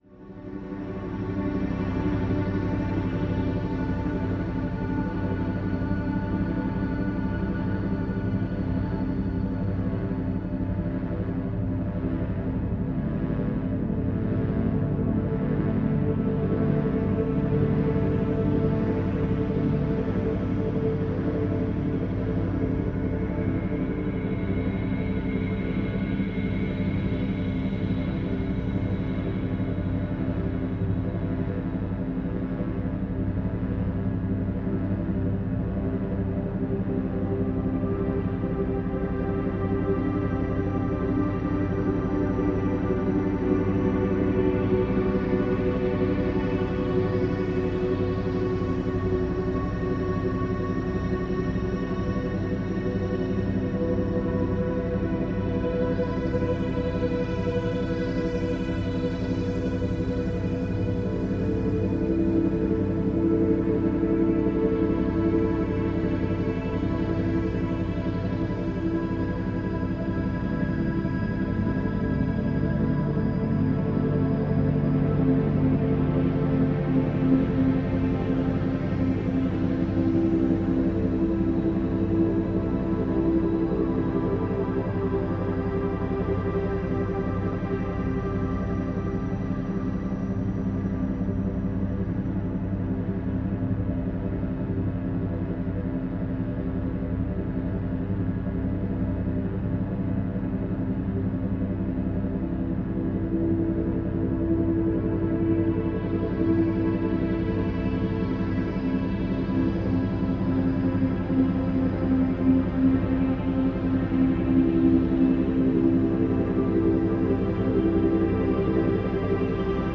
Mental Running - Motivation zum Laufen mit Electronic Beats und 100% Regeneration
Hörbuch